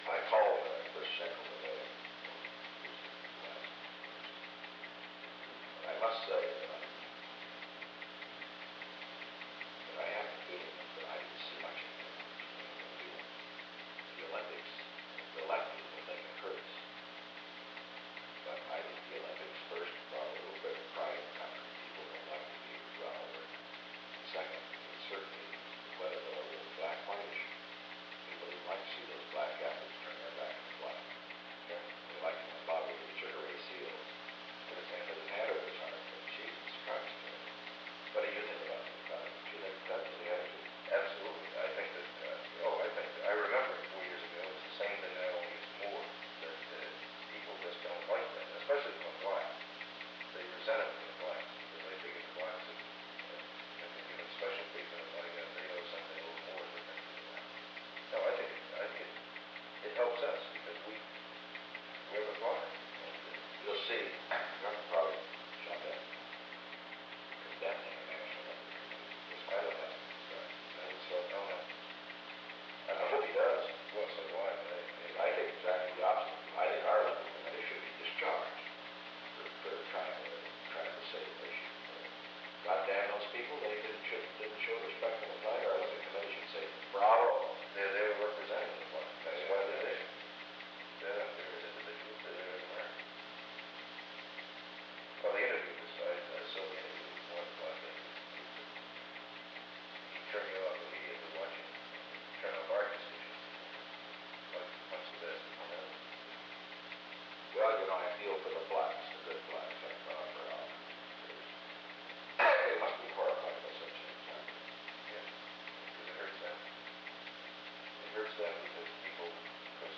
The Presidency / Featured Content 'Respect for the Flag' 'Respect for the Flag' Photo: US Navy At the Olympic Games in Munich, two Black athletes, track stars Vincent Matthews and Wayne Collette, had been perceived as acting disrespectfully during a medal ceremony. President Nixon and presidential assistant Charles W. “Chuck” Colson discussed this recent controversy and its implications for perceptions of Black Americans writ large. Date: September 11, 1972 Location: Executive Office Building Tape Number: 360-012 Participants Richard M. Nixon Charles W. “Chuck” Colson Associated Resources Audio File Transcript